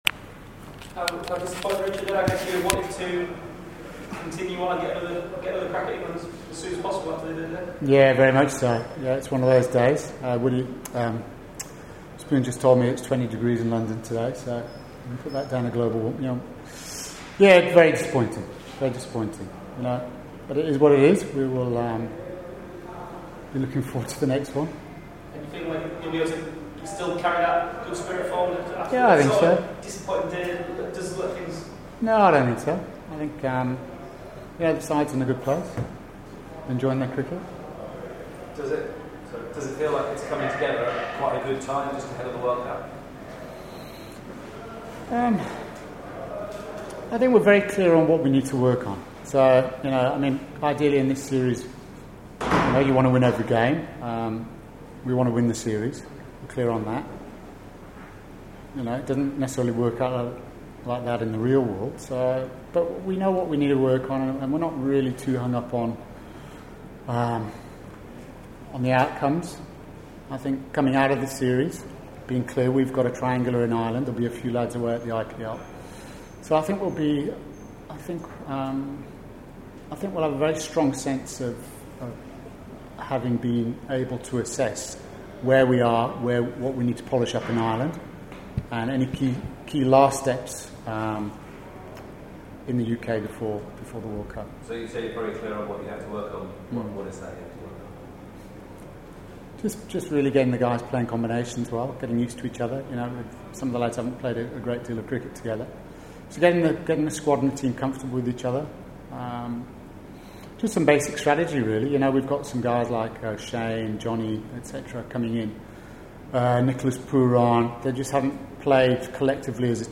spoke to members of the media after the third Colonial Medical Insurance One-Day International against England was abandoned due to rain.